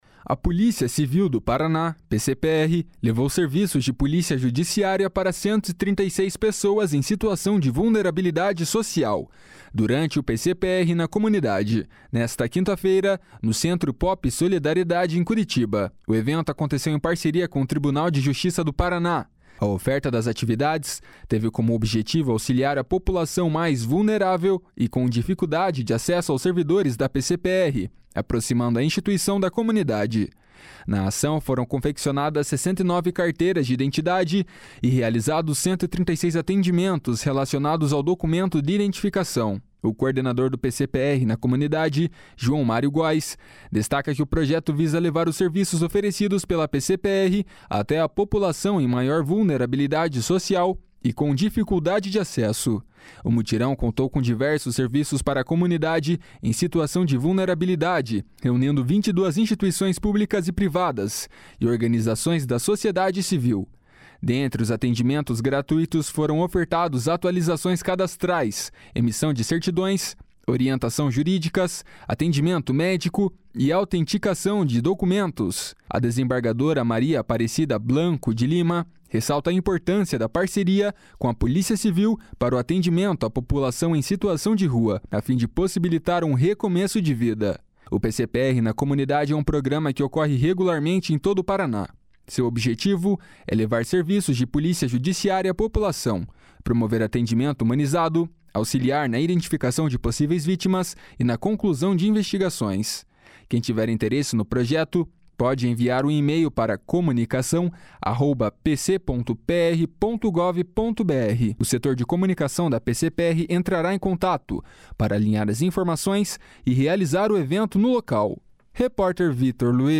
A desembargadora Maria Aparecida Blanco de Lima ressalta a importância da parceria com a Polícia Civil para o atendimento à população em situação de rua, a fim de possibilitar um recomeço de vida.